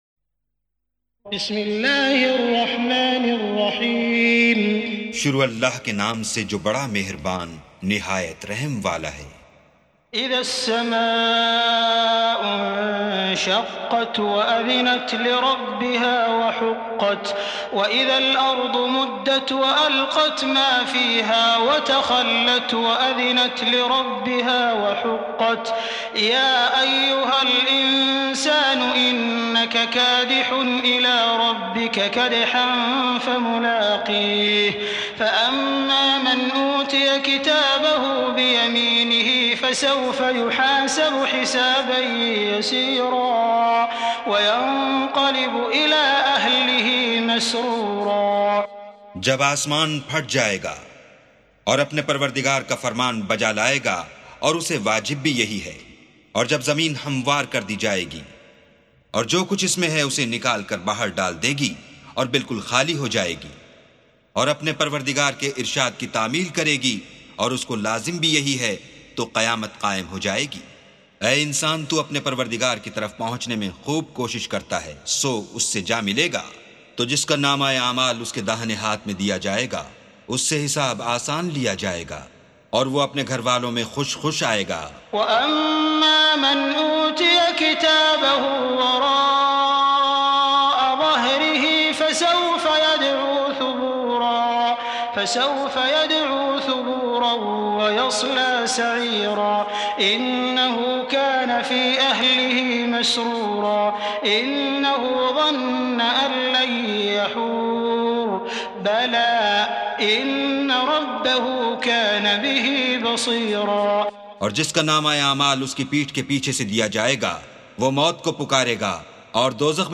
سُورَةُ الانشِقَاقِ بصوت الشيخ السديس والشريم مترجم إلى الاردو